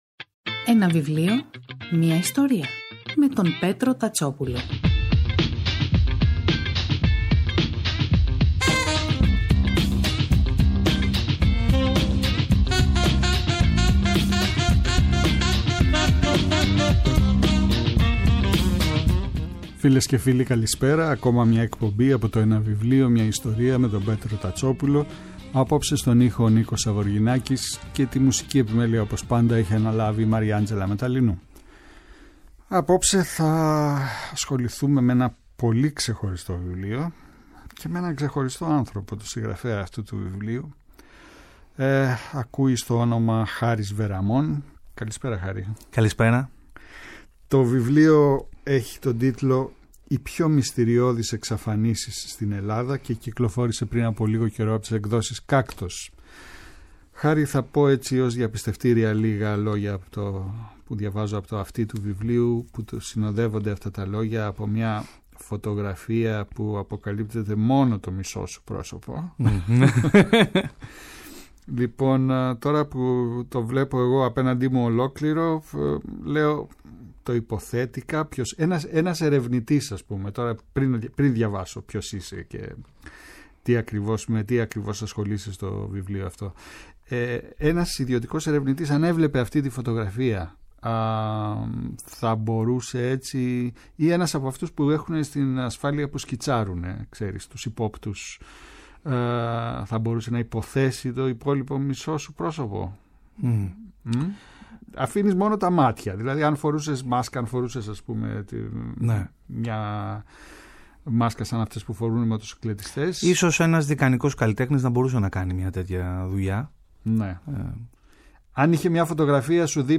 Κάθε Σάββατο και Κυριακή, στις 5 το απόγευμα στο Πρώτο Πρόγραμμα της Ελληνικής Ραδιοφωνίας ο Πέτρος Τατσόπουλος, παρουσιάζει ένα συγγραφικό έργο, με έμφαση στην τρέχουσα εκδοτική παραγωγή, αλλά και παλαιότερες εκδόσεις. Η γκάμα των ειδών ευρύτατη, από μυθιστορήματα και ιστορικά μυθιστορήματα, μέχρι βιογραφίες, αυτοβιογραφίες και δοκίμια.